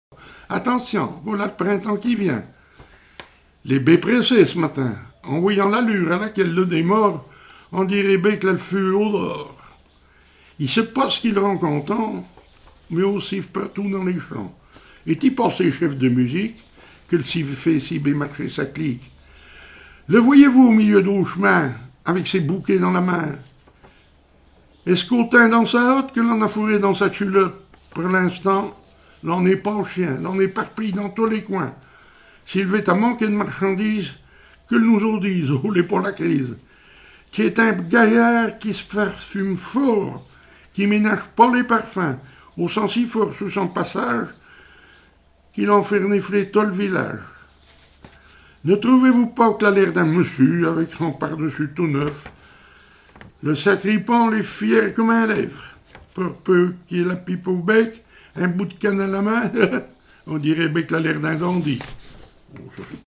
Patois local
Genre poésie
Catégorie Récit